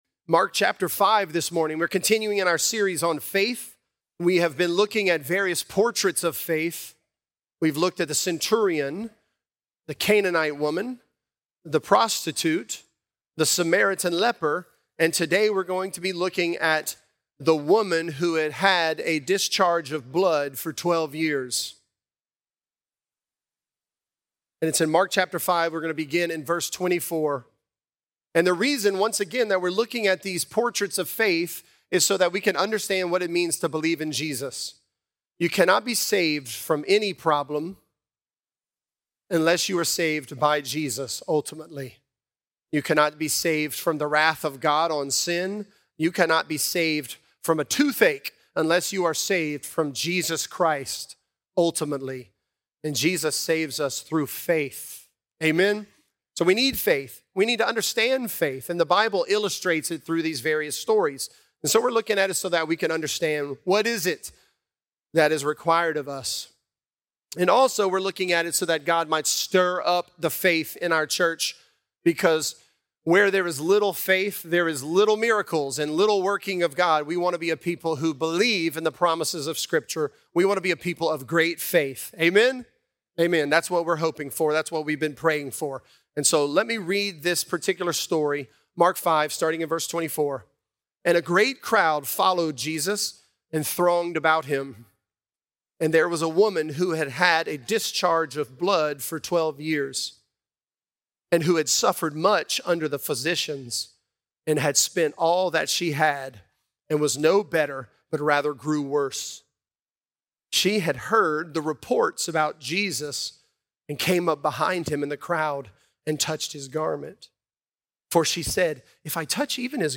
Mountain Moving Faith: Who Touched My Garment | Lafayette - Sermon (Mark 5)